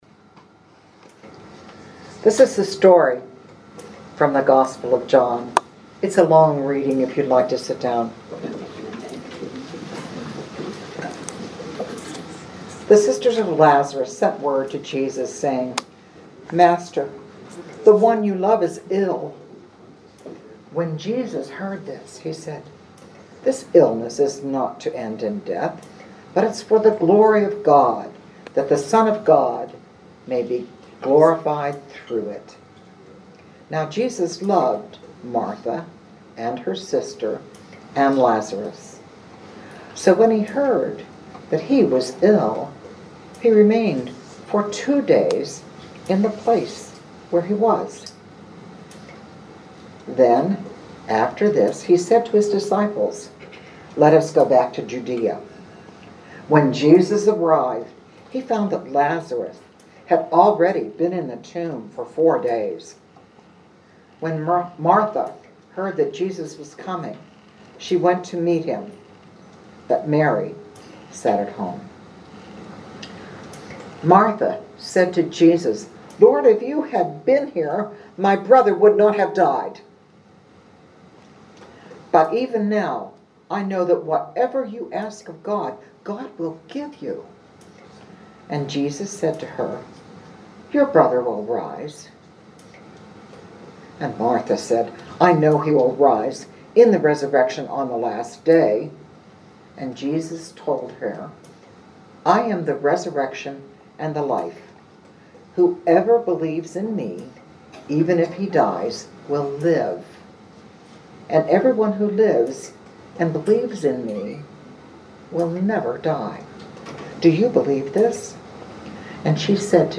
Living Beatitudes Community Homilies: Step Out Into a Life of Love